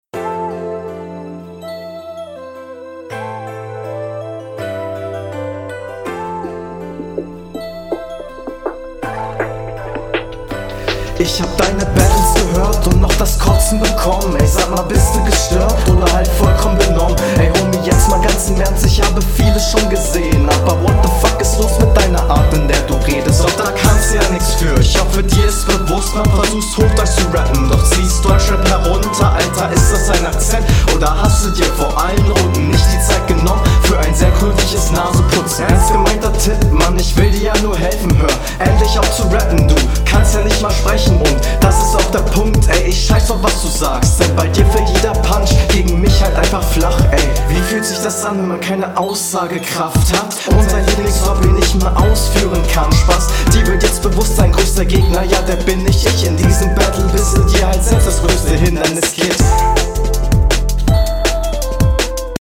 Auch wenn im ersten Moment vielleicht nicht unbedingt "Battlestimmung" aufkommt, passt deine Stimme gut zum …
Nicer Beatpick!